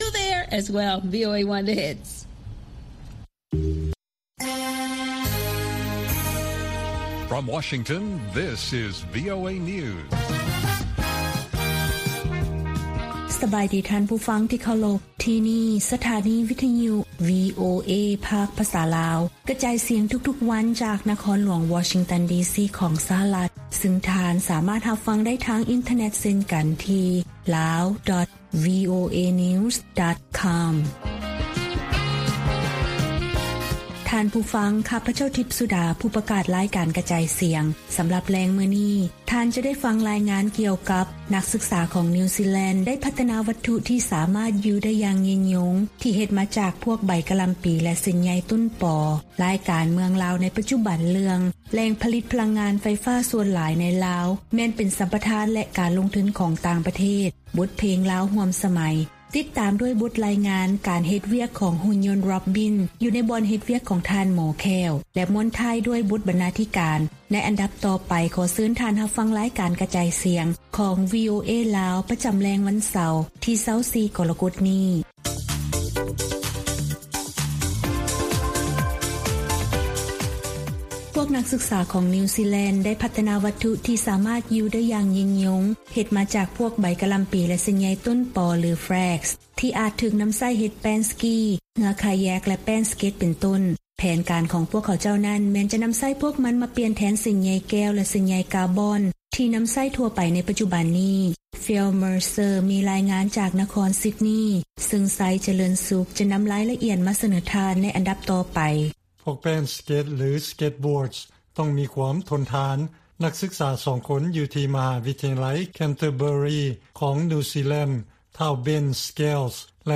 ລາຍການກະຈາຍສຽງຂອງວີໂອເອ ລາວ: ສ່ວນໃຫຍ່ຂອງ 81 ເຂື່ອນເປັນຂອງຕ່າງຊາດ ສ່ວນລາວເປັນເຈົ້າຂອງພຽງແຕ່ 10 ເຂື່ອນ
ວີໂອເອພາກພາສາລາວ ກະຈາຍສຽງທຸກໆວັນ. ຫົວຂໍ້ຂ່າວສໍາຄັນໃນມື້ນີ້ມີ: 1) ສ່ວນໃຫຍ່ຂອງ 81 ເຂື່ອນເປັນຂອງຕ່າງຊາດ ສ່ວນລາວເປັນເຈົ້າຂອງພຽງແຕ່ 10 ເຂື່ອນ.